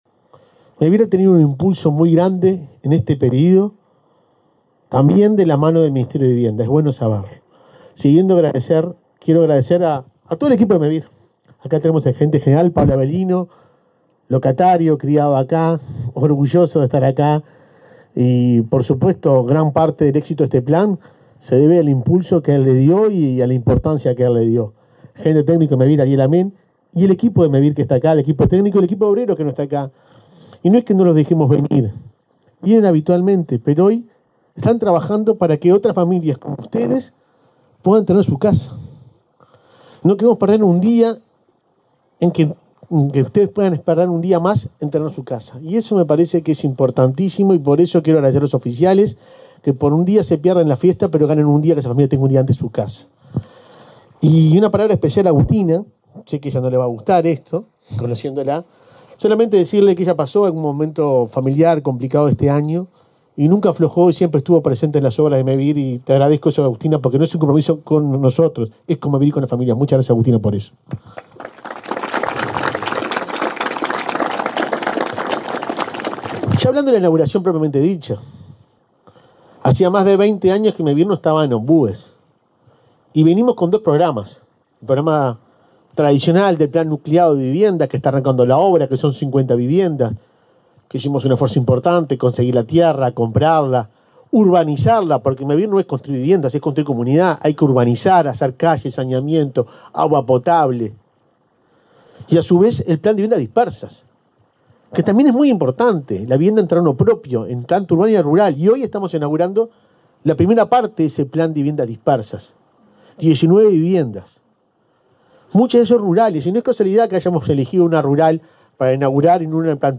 Palabras de autoridades en inauguración de Mevir en Colonia
Palabras de autoridades en inauguración de Mevir en Colonia 20/04/2023 Compartir Facebook X Copiar enlace WhatsApp LinkedIn Mevir inauguró 19 soluciones habitacionales en Ombúes de Lavalle, departamento de Colonia. El presidente de la institución, Juan Pablo Delgado, y el subsecretario de Vivienda, Tabaré Hackenbruch, señalaron la importancia de las obras.